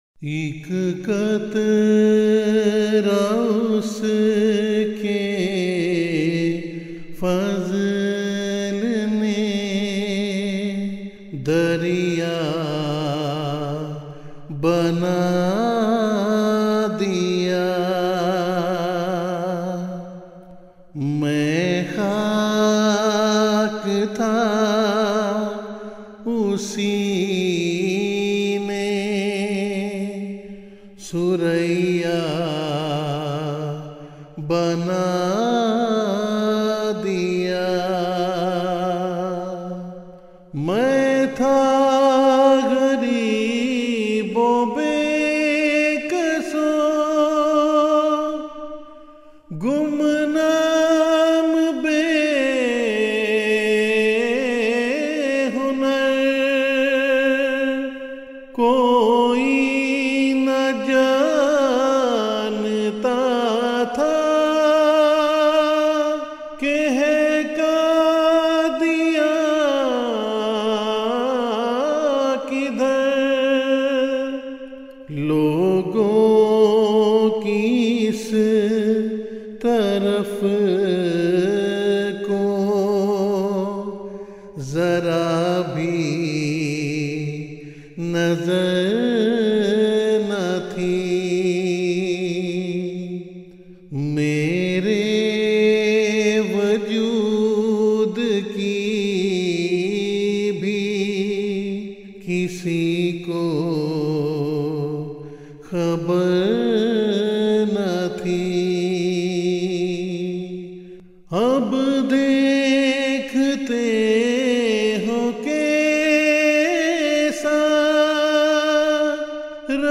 Urdu Poems
Voice: Member Khuddamul Ahmadiyya